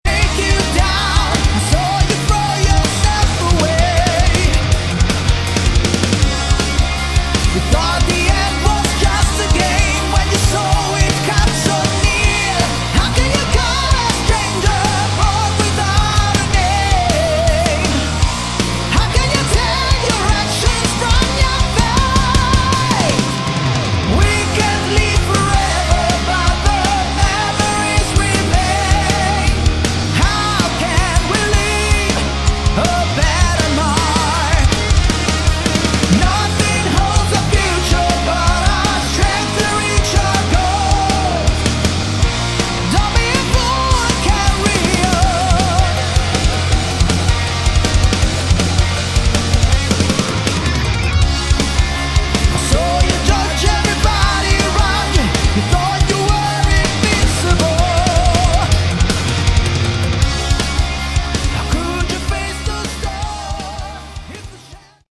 Category: Hard Rock
vocals, keyboards
guitar
bass
drums